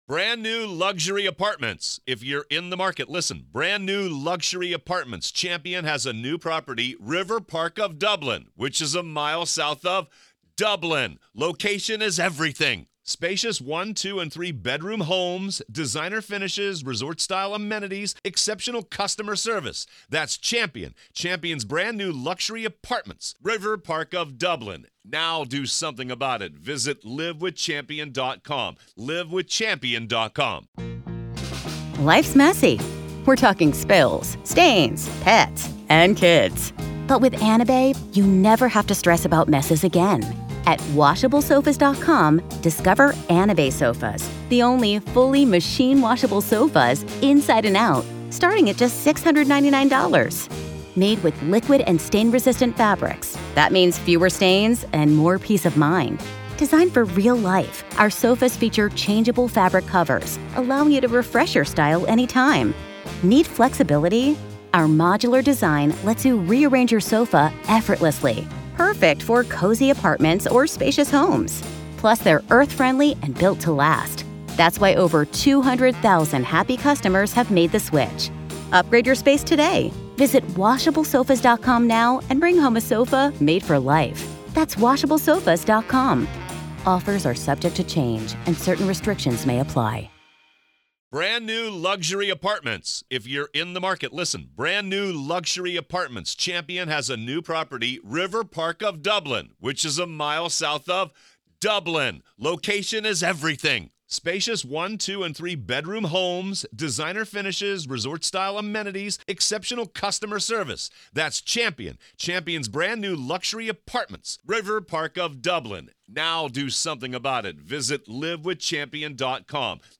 Alex Murdaugh Trial: Courtroom Coverage | Day 4, Part 5